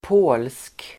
Uttal: [på:lsk]